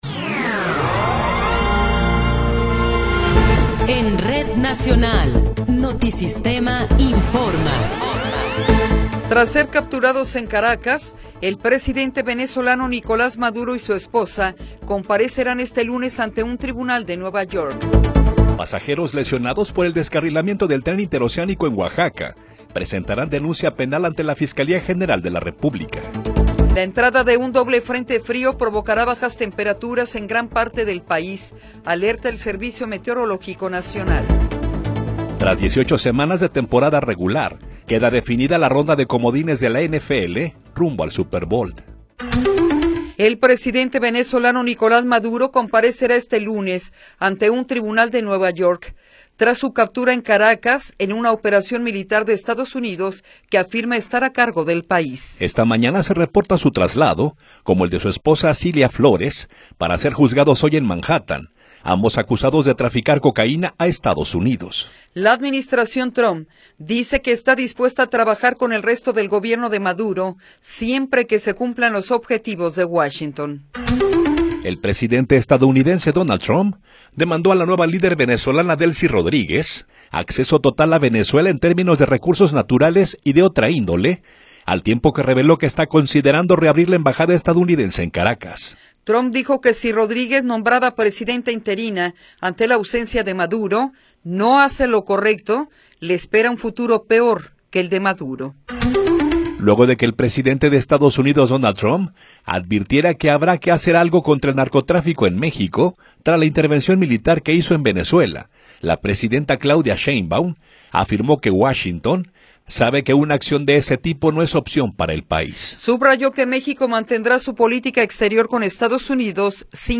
Noticiero 8 hrs. – 5 de Enero de 2026